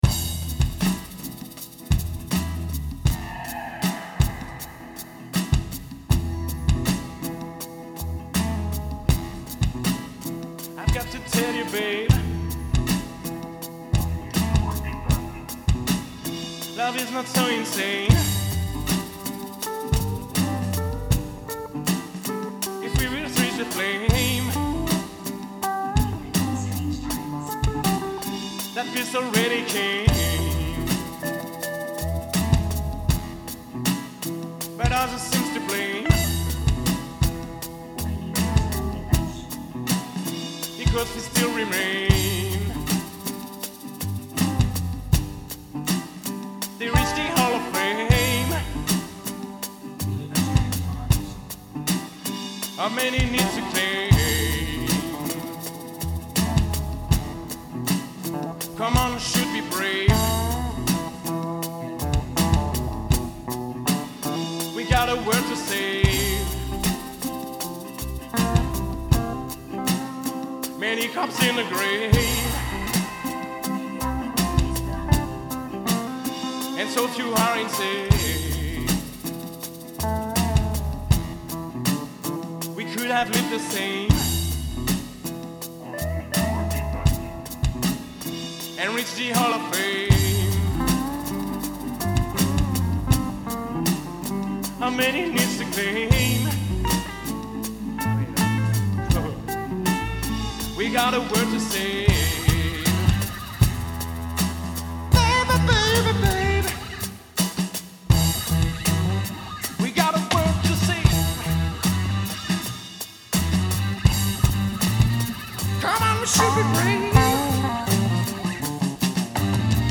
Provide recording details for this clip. This Sample below is what we were looking for , what we dig, in order to make a new song We play $tµff , we record it, make cuttings and so we have a begining of an idea of a song